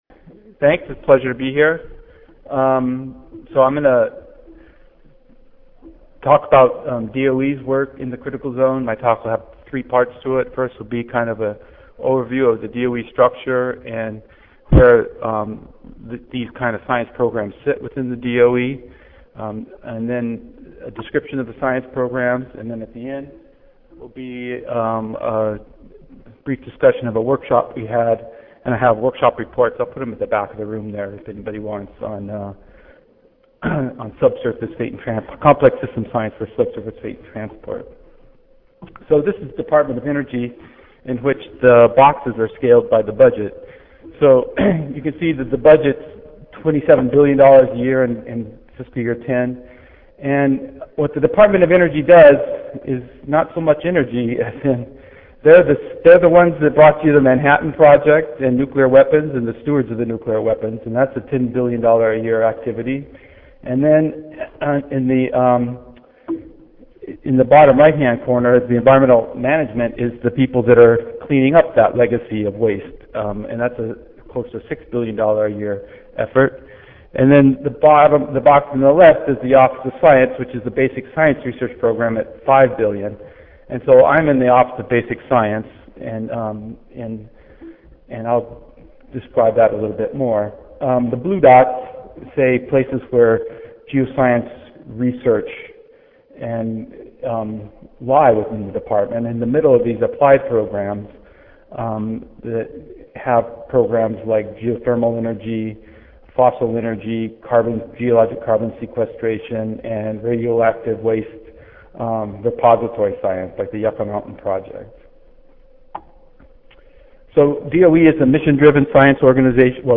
U. S. Department of Energy Audio File Recorded presentation